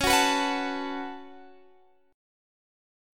Db7sus4#5 chord